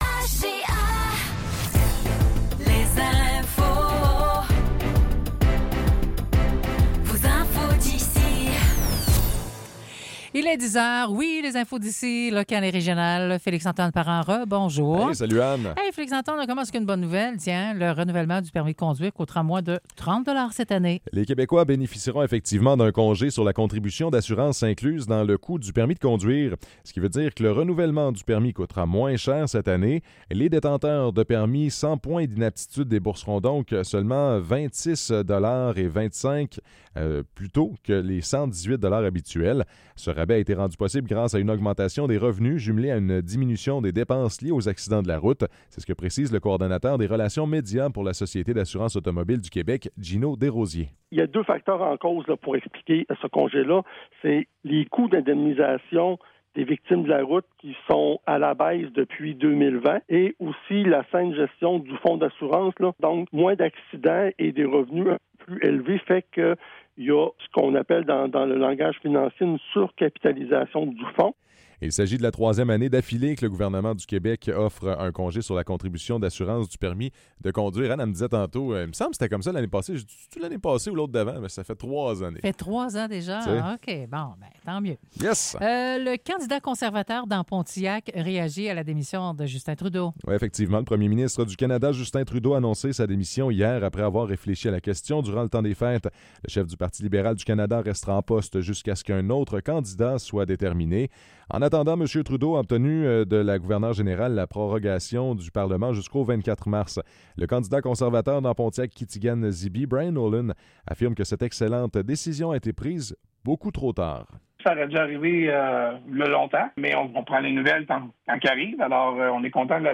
Nouvelles locales - 7 janvier 2025 - 10 h